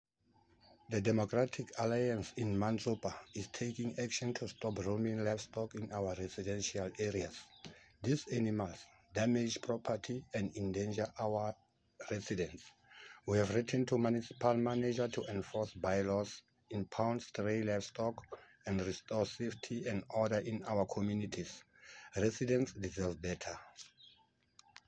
Sesotho soundbites by Cllr Leonard Masilo.